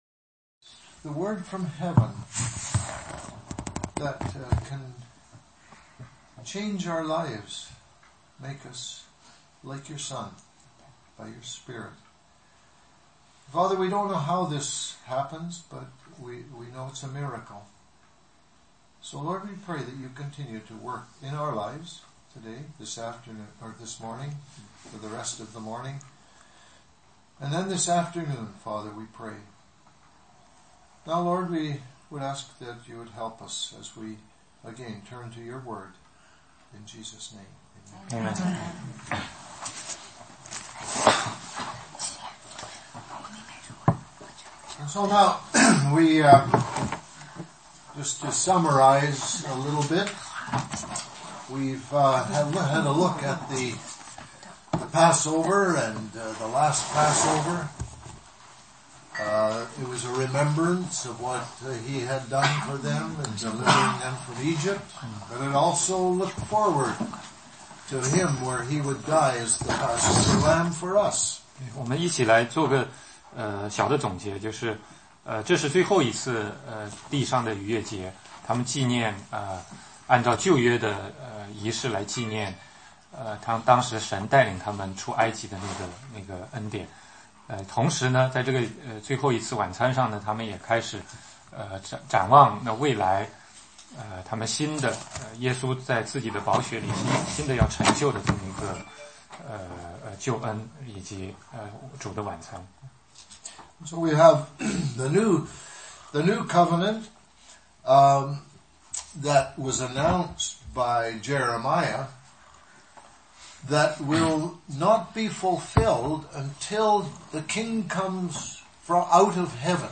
16街讲道录音 - 路加福音